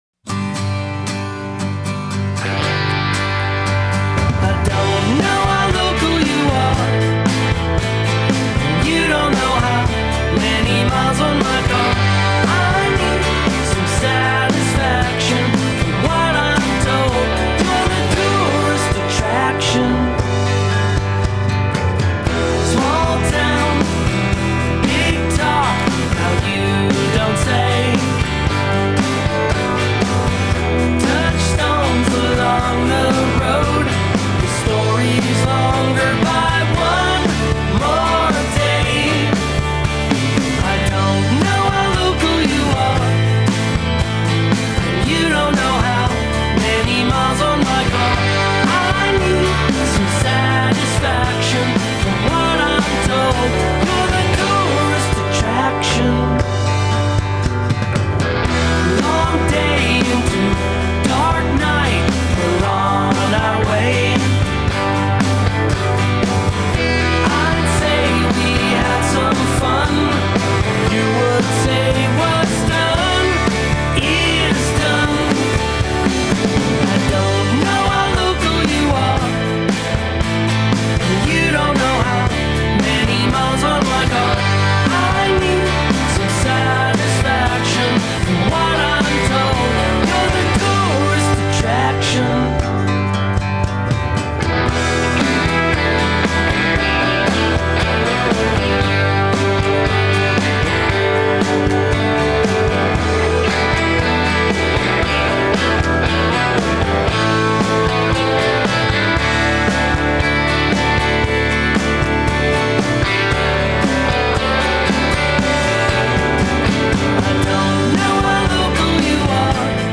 The magnetic pull of this tune is subtle but strong.
Descriptors like ‘Beatlesque’ were fairly common.